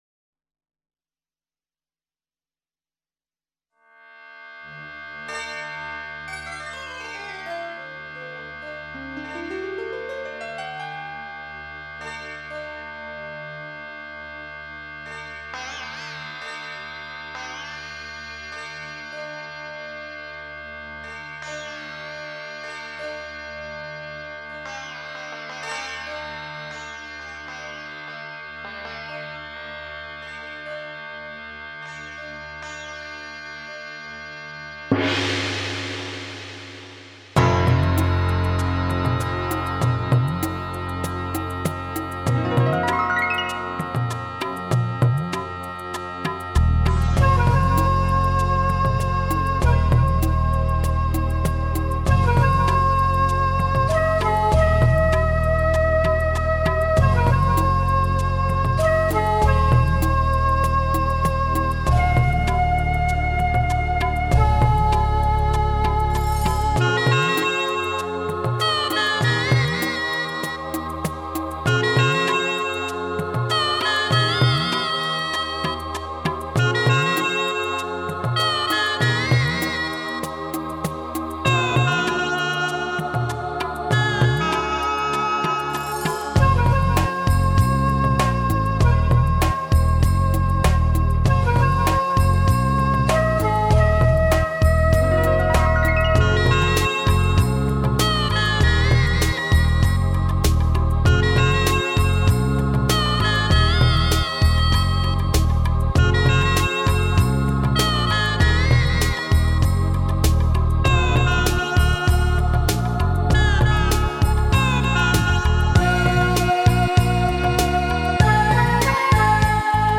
纯音乐 地区
音乐雄伟壮丽，如阳光耀眼； 浏畅的和弦、丰满的配器，热闹和谐。